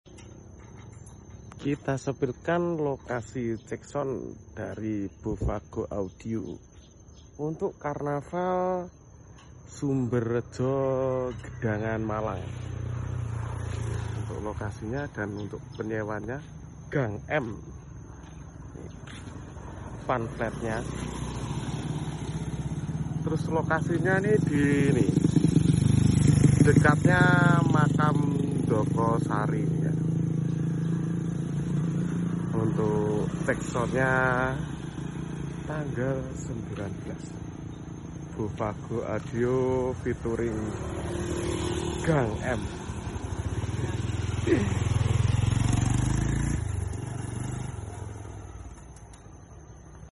Bofago audio lokasi cek sound karnaval sumberjo gedangan malang 2025